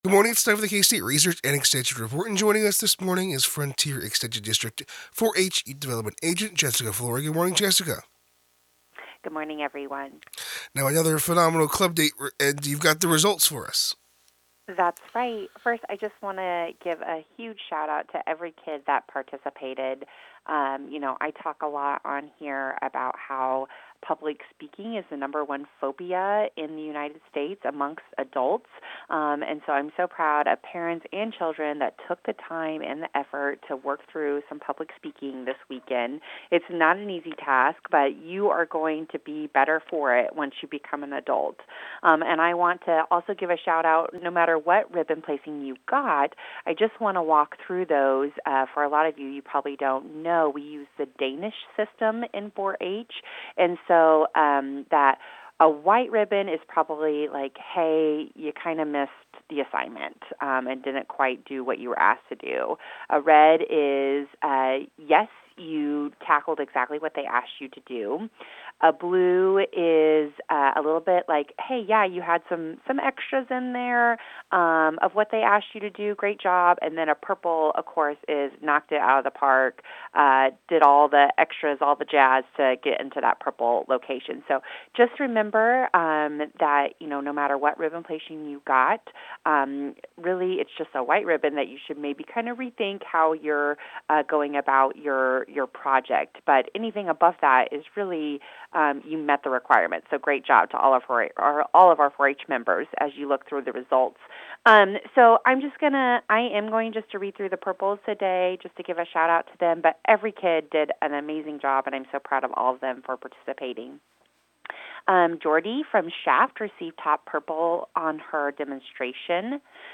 KOFO Radio 2026 Recordings – Local Broadcast Audio Archive